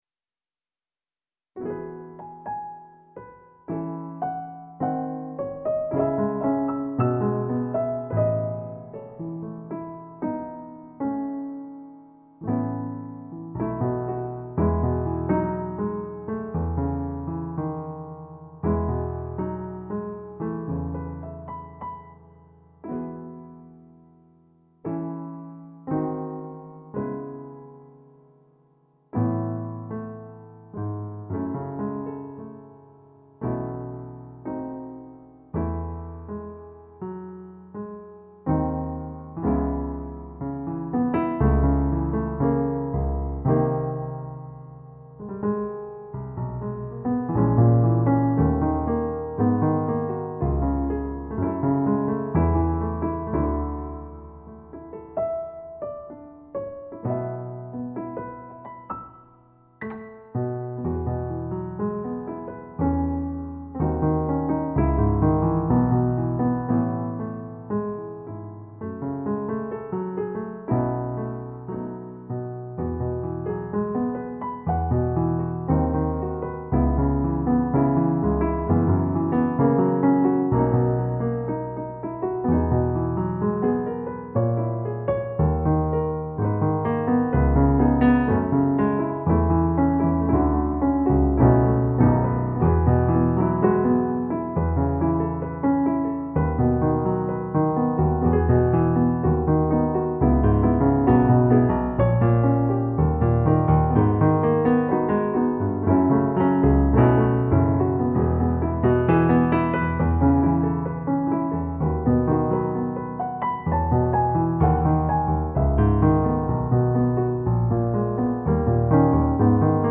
YT Piano Accompaniment